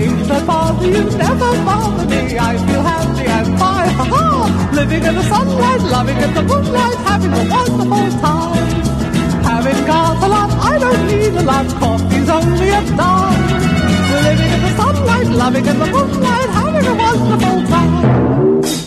• Качество: 133, Stereo
поп
позитивные
веселые
Lounge
ретро